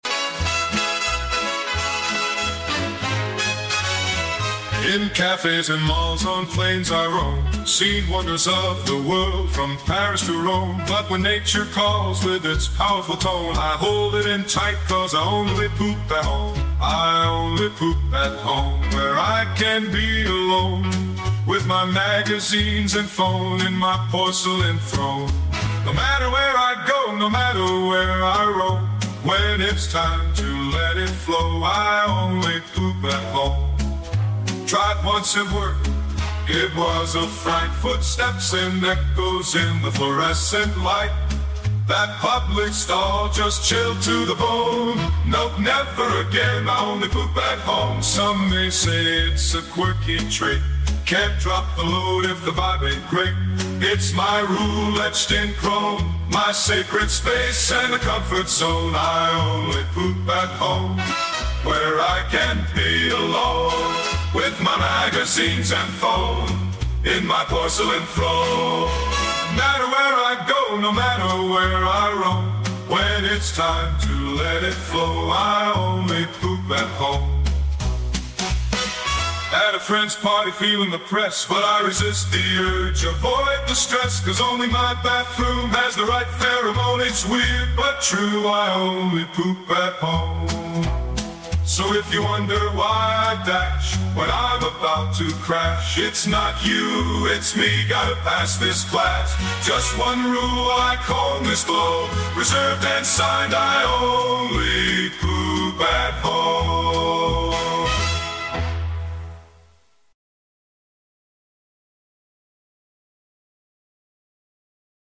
Obscure Vinyl AI Generated 50s Music